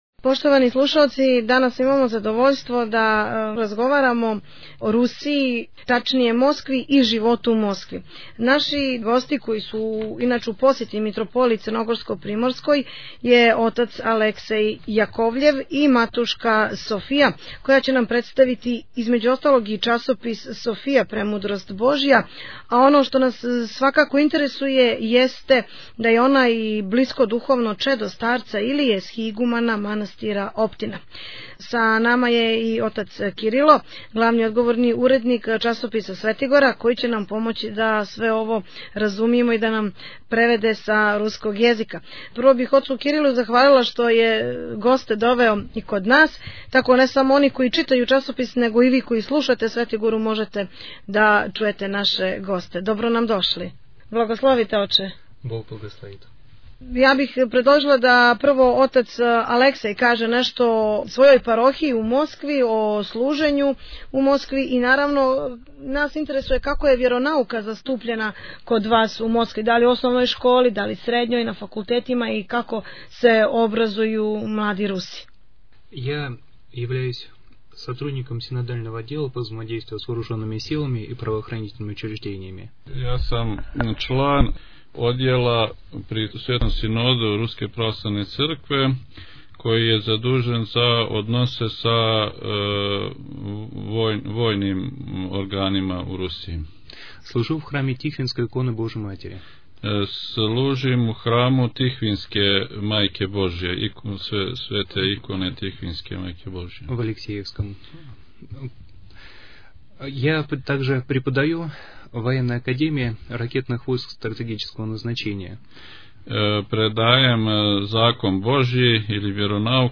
Они у разговору за наш радио говоре о тренутној ситуацији у Русији када је ријеч о вјерском образовању, о новом покрету "Нови Хуманизам" који је у супротности са православним учењем те о старцу Илији, његовом животу и поукама којима их кријепи.